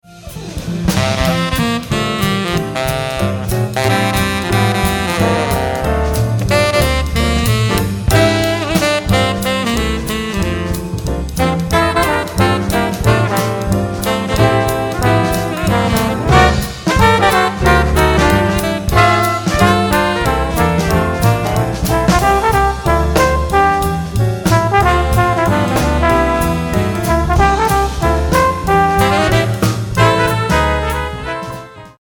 • Studio: Studio 12, Société Radio-Canada (Montréal)